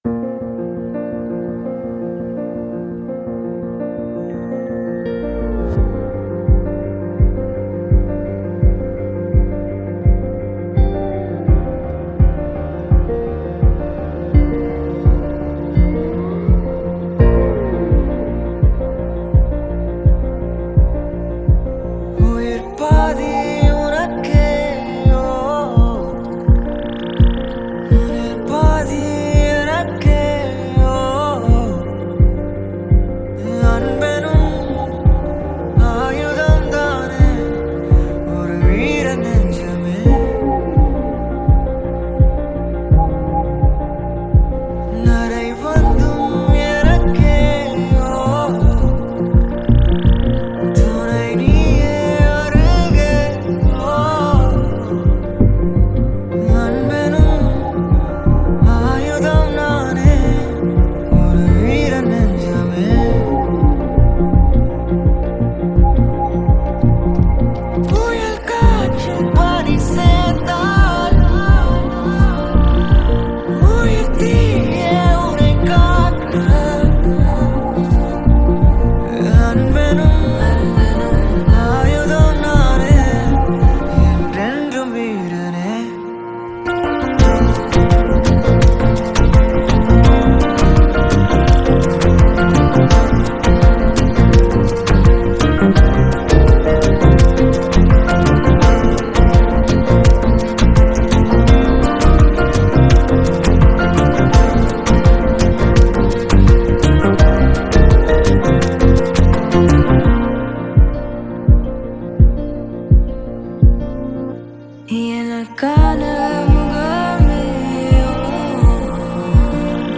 Tamil Movie Songs